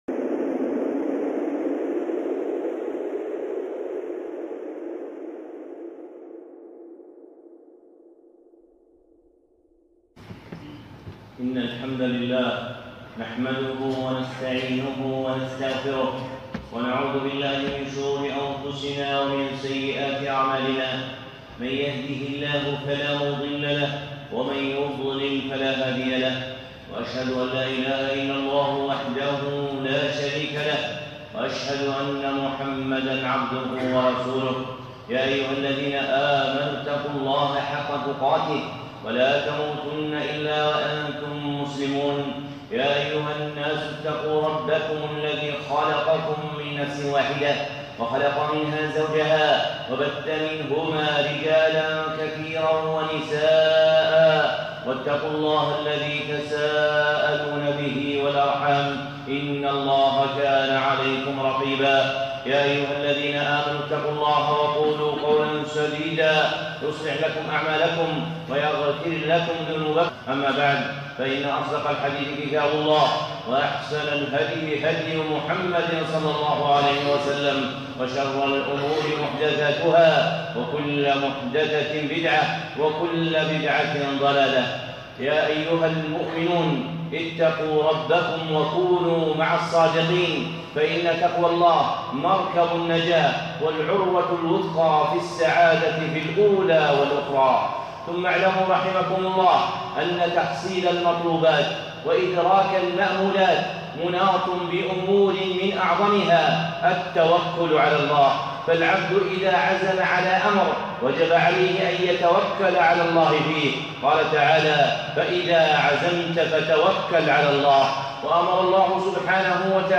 خطبة (التوكل وما يضاده)